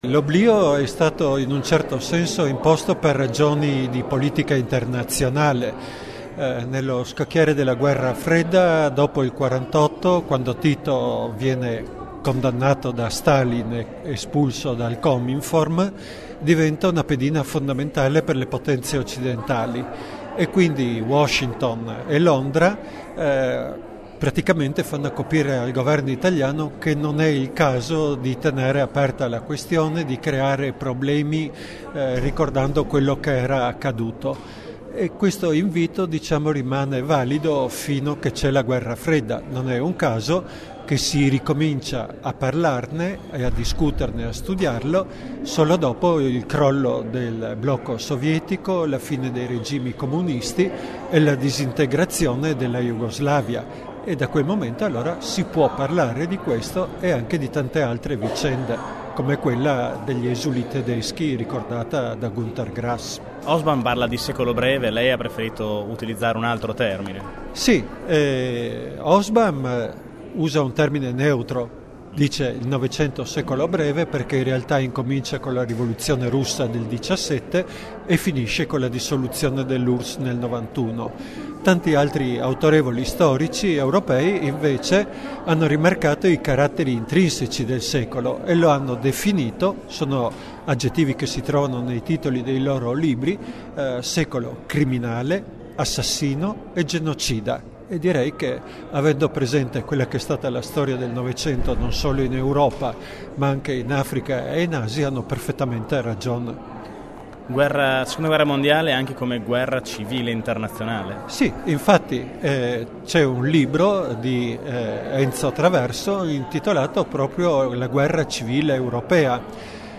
durante il consiglio comunale straordinario dedicato al “Giorno del Ricordo“.